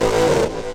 tekTTE63030acid-A.wav